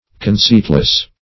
Search Result for " conceitless" : The Collaborative International Dictionary of English v.0.48: Conceitless \Con*ceit"less\, a. Without wit; stupid.
conceitless.mp3